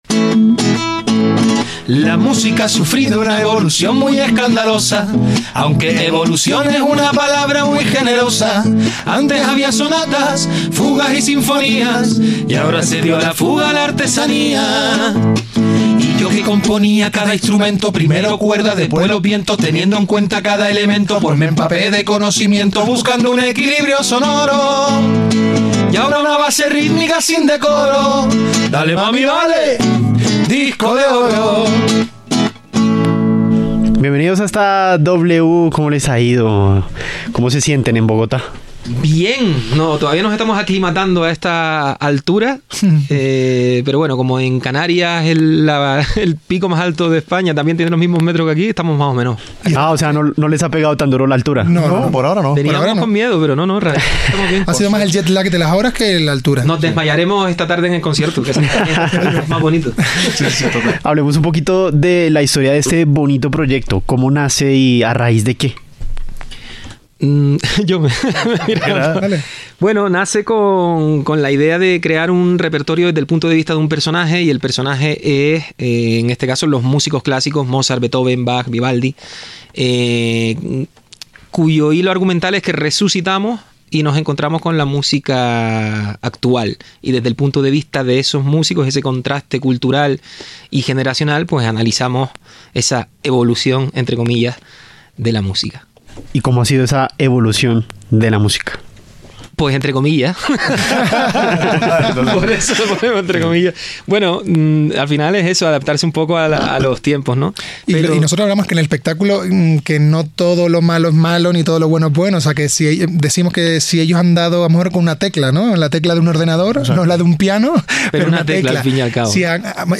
El espectáculo critica el reguetón, pero al mismo tiempo reconocemos que algo están haciendo bien”, comentaron durante la entrevista, dejando claro que su obra no se trata de una negación de los nuevos géneros, sino de una reflexión sobre cómo la música ha evolucionado y cómo los tiempos cambian.